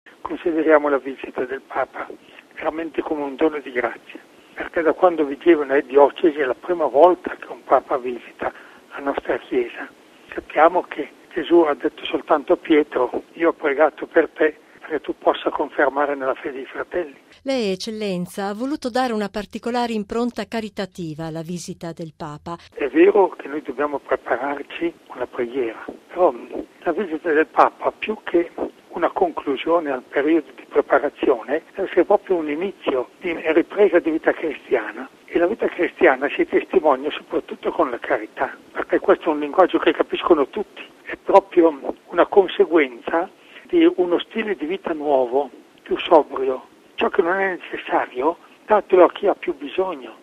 Una visita cui il vescovo mons. Claudio Baggini ha voluto dare una particolare nota caritativa.